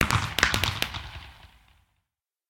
twinkle_far.ogg